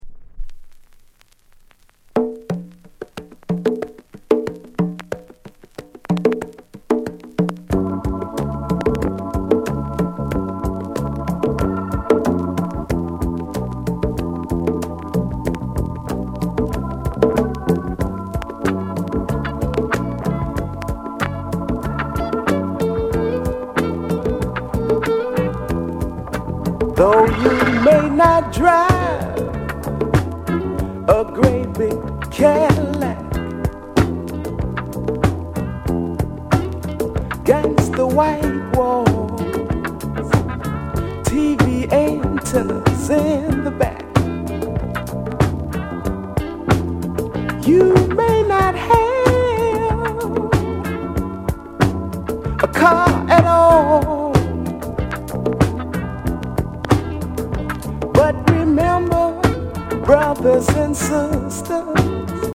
SOUL CLASSICS